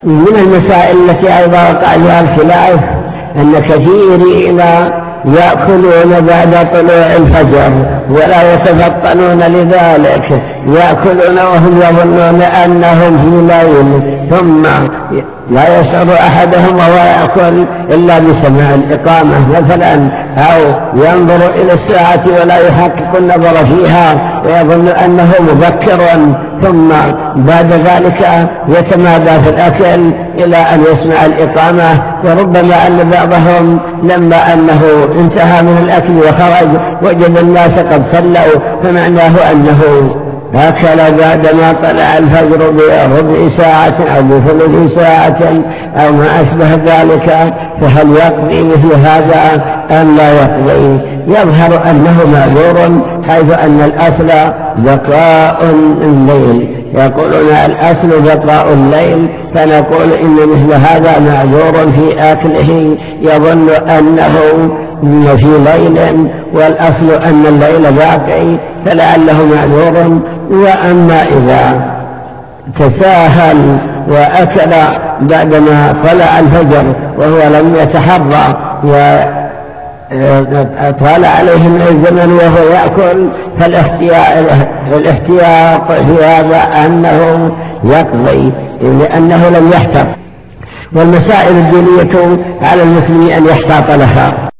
المكتبة الصوتية  تسجيلات - محاضرات ودروس  الافتراق والاختلاف الكلام عن الخلاف وأسبابه